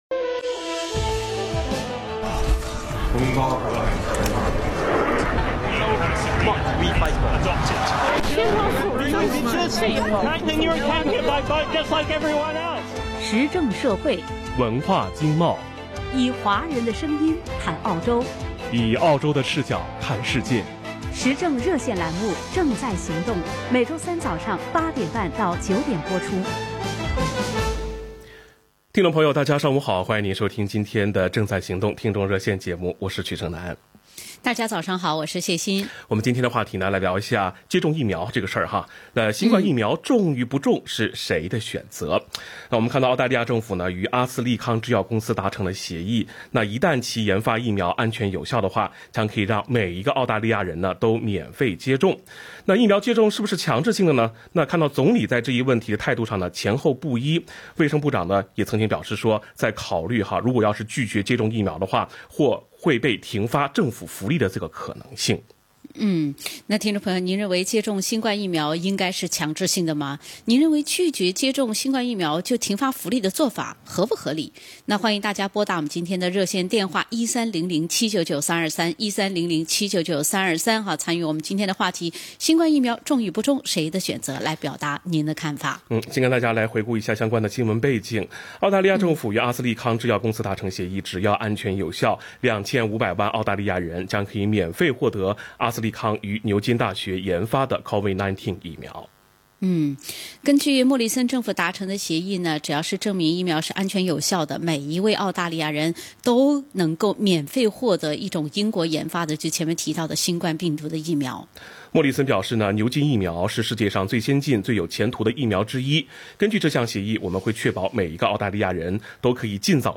action_talkback_august_26.mp3